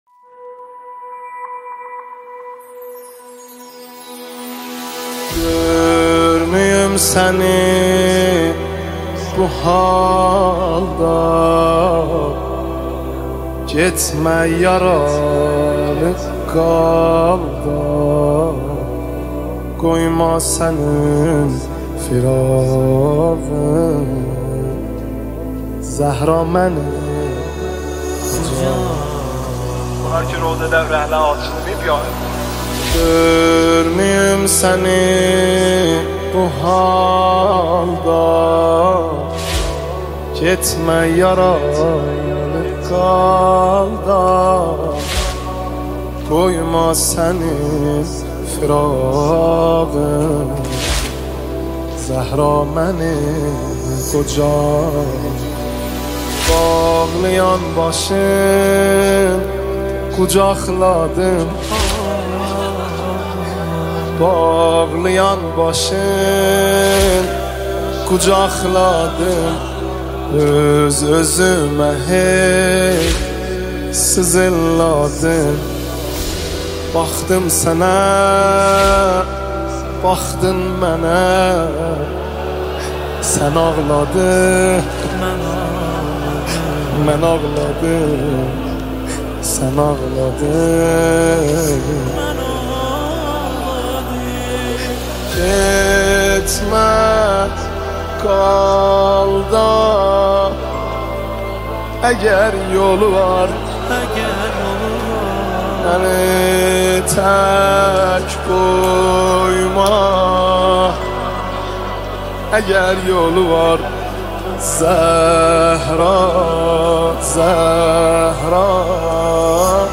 نواهنگ